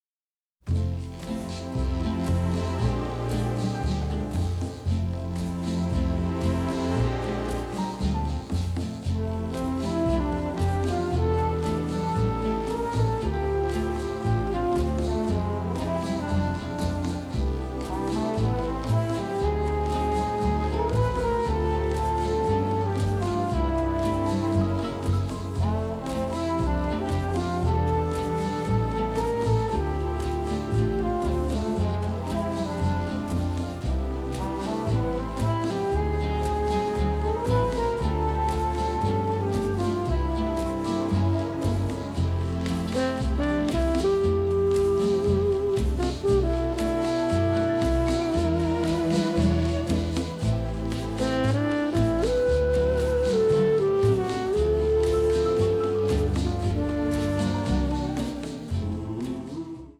stereo